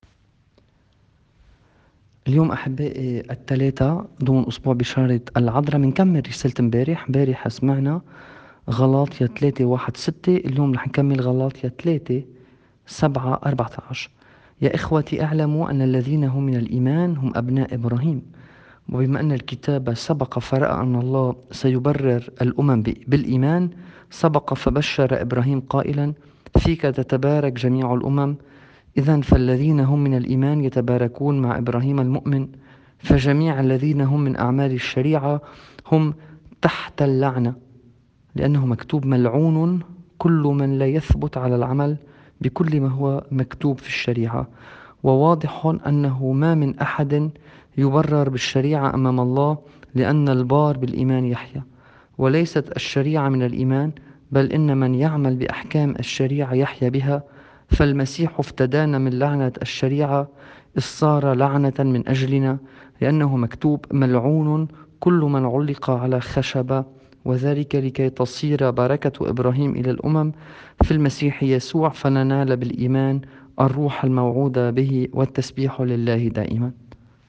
الرسالة بحسب الطقوس المارونية واللاتينية والبيزنطية
الطقس الماروني